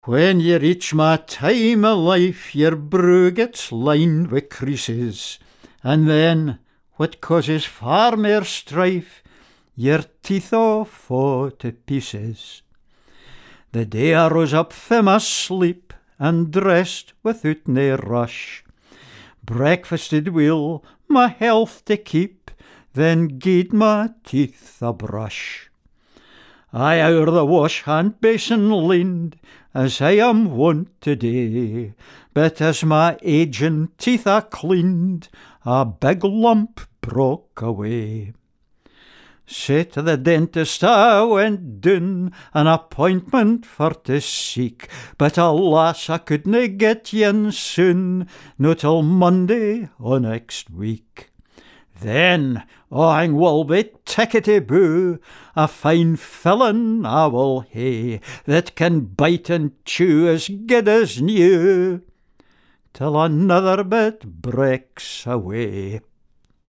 Scots Song